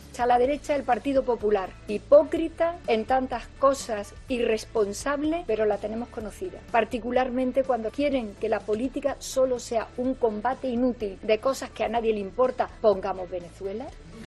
AUDIO: Declaraciones de Calvo durante una reunión esta mañana en Ferraz